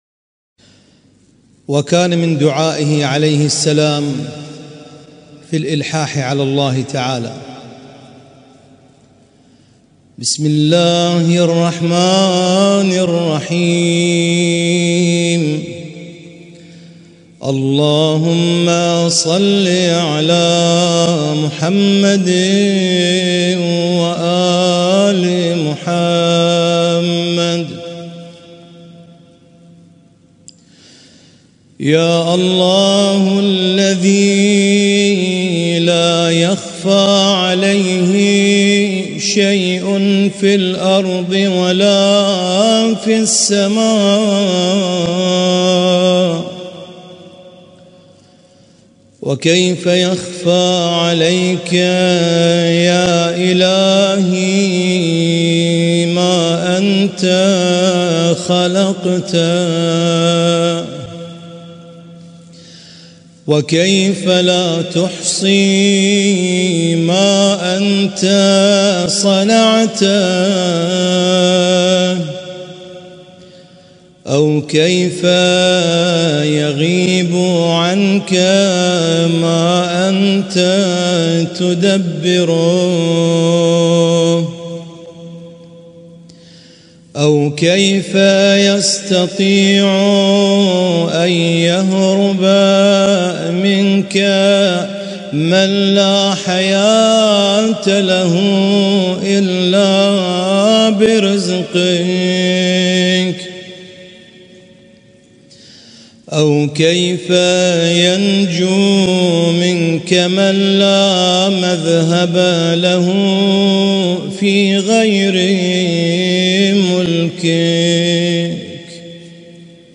اسم التصنيف: المـكتبة الصــوتيه >> الصحيفة السجادية >> الادعية السجادية